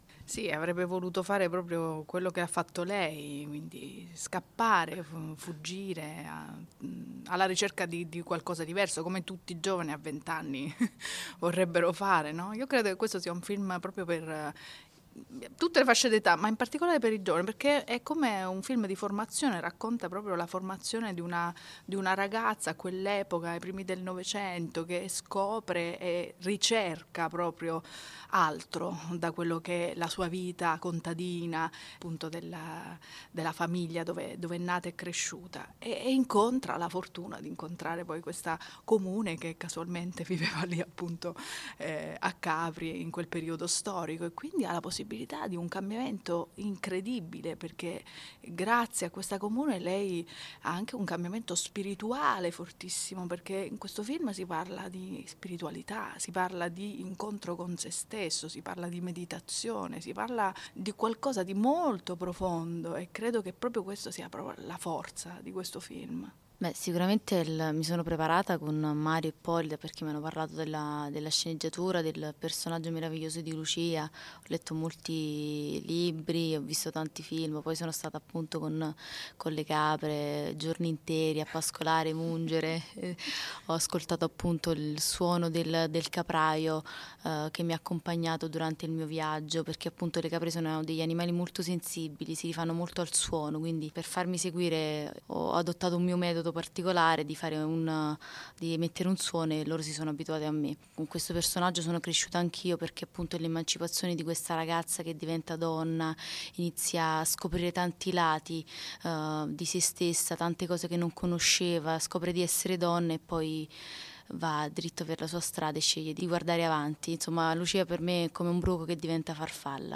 capri-revolution-gli-attori-presentano-il-film.mp3